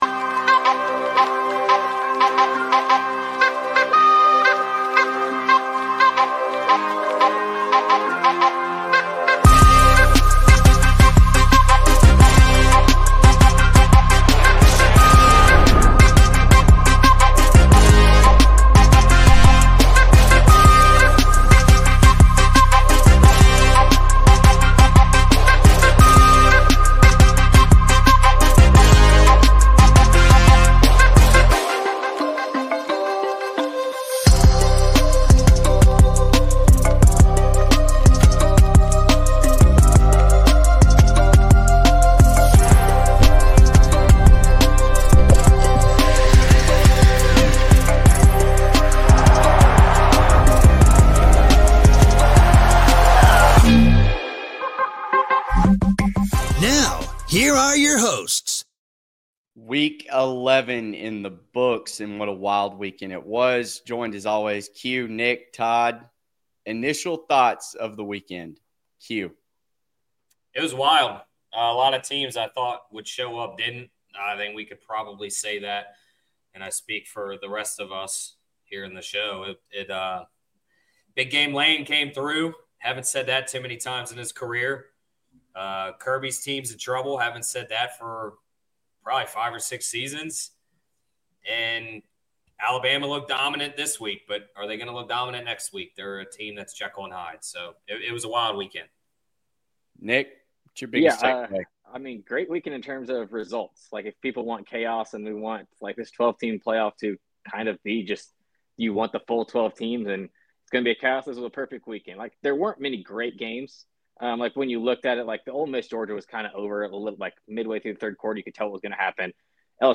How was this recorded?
Join the live stream of the College Football Insiders Show as the guys recap what they saw in Week 11 of College Football, update you on the weekly pick’em contest, and preview the slate ahead.